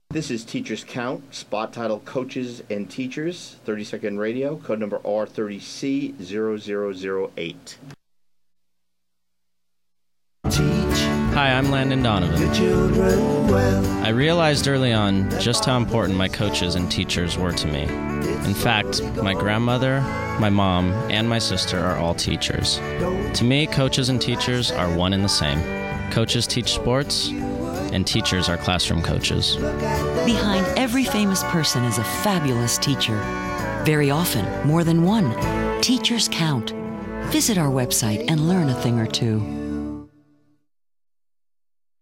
Landon_Donovan_TeachersCount_PSA_Radio_.mp3